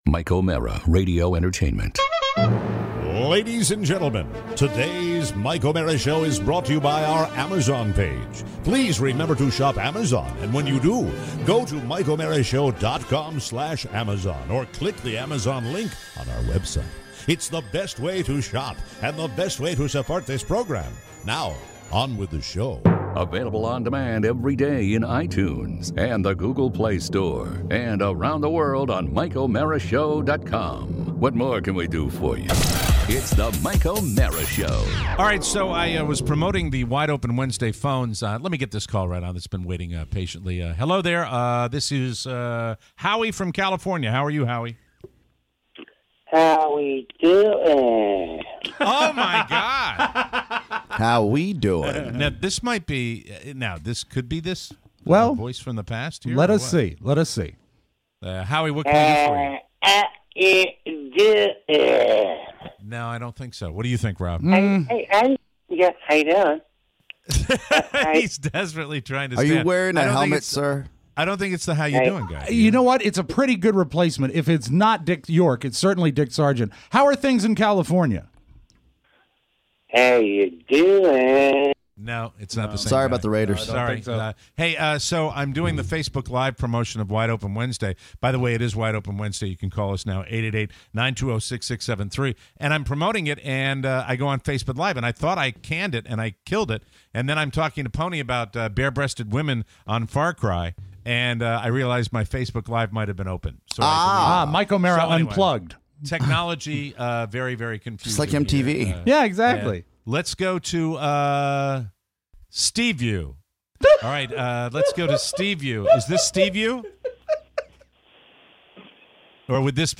Your calls… Plus; the Rink of Hate, cat fights, “The Departure”, and the greatest linebacker of all time.